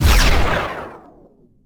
enemybeams.wav